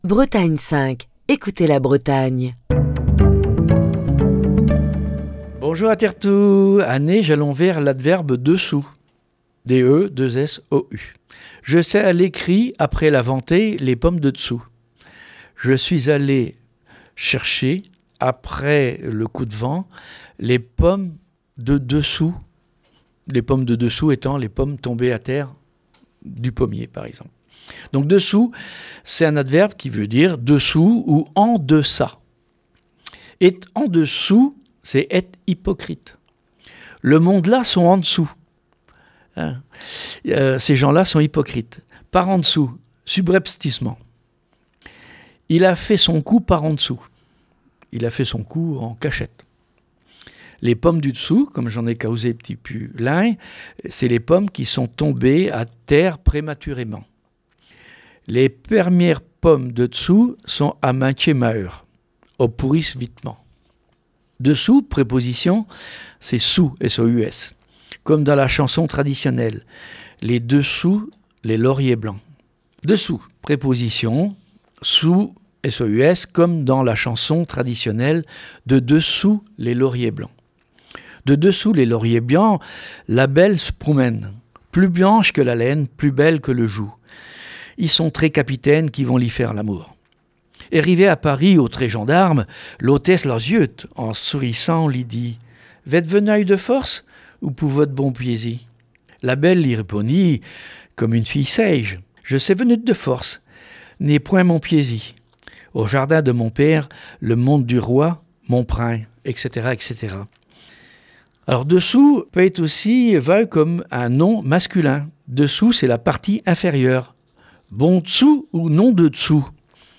Chronique du 11 février 2022.